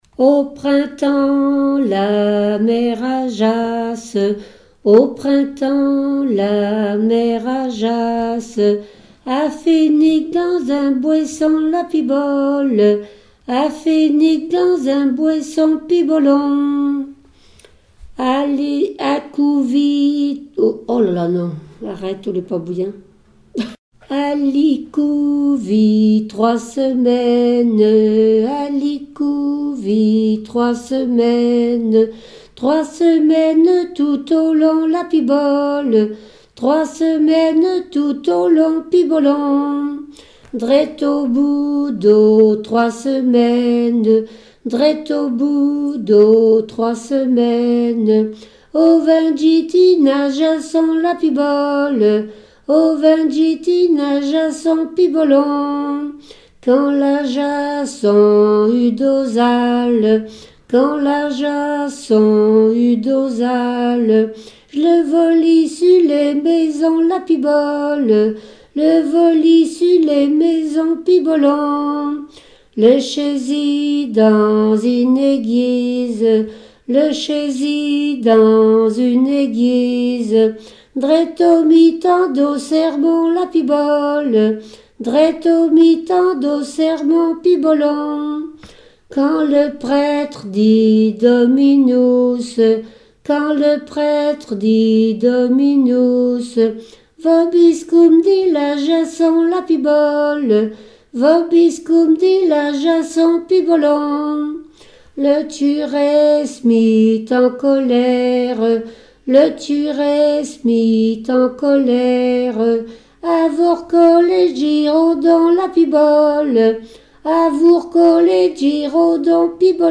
Mémoires et Patrimoines vivants - RaddO est une base de données d'archives iconographiques et sonores.
Genre laisse
répetoire de chansons traditionnelles et populaires
Pièce musicale inédite